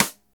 SNR SOLID 0C.wav